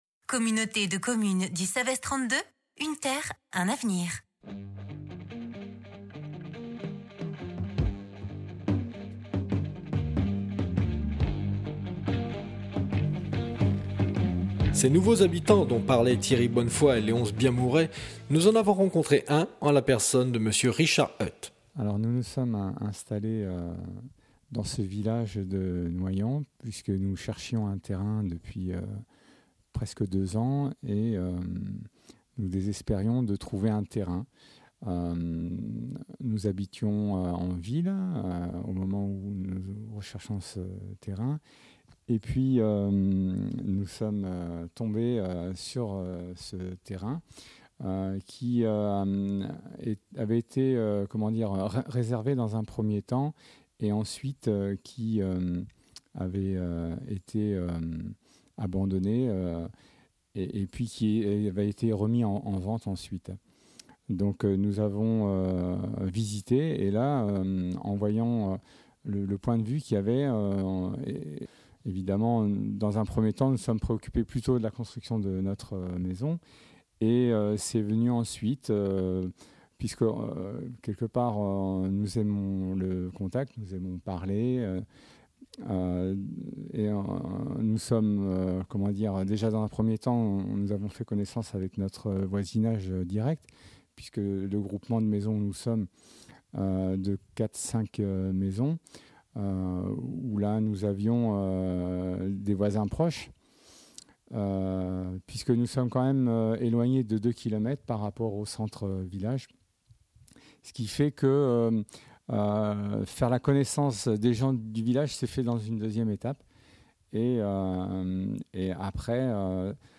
Première partie du reportage sonore sur Noilhan